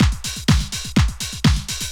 Event Beat 5_125.wav